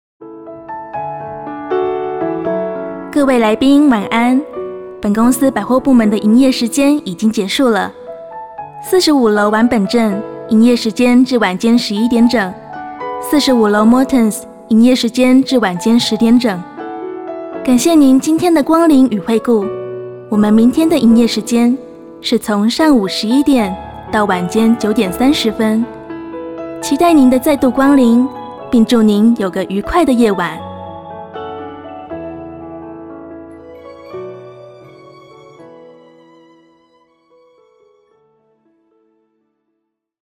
电台主播-深情